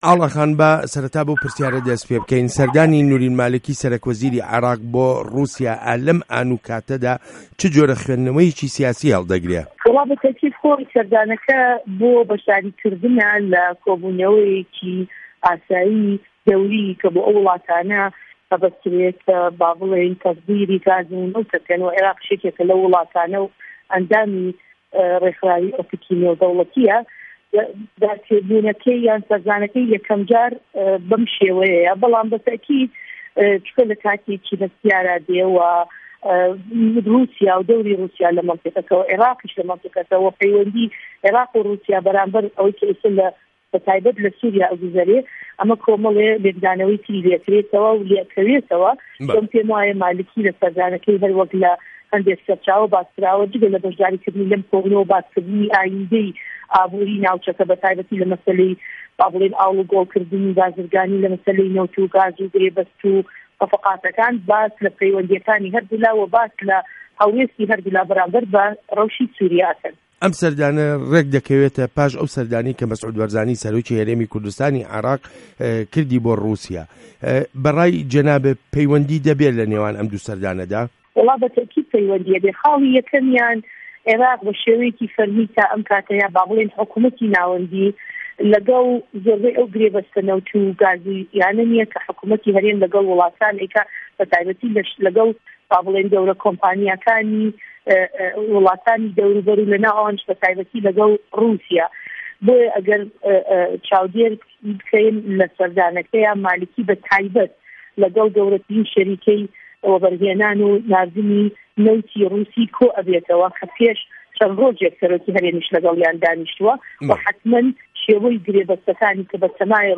وتووێژ له‌گه‌ڵ ئاڵا تاڵه‌بانی